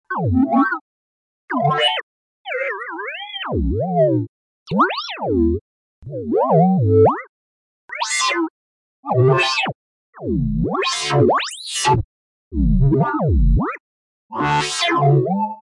Download Robot sound effect for free.
Robot